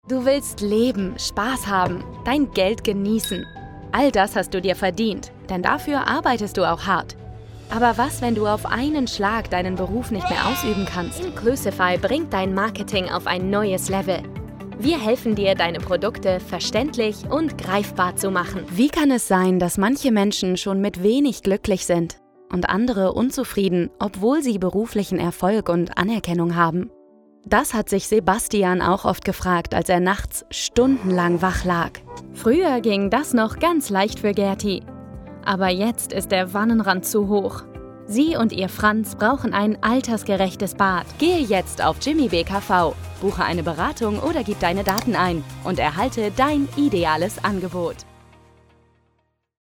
Natürlich, Cool, Verspielt, Vielseitig, Freundlich
Unternehmensvideo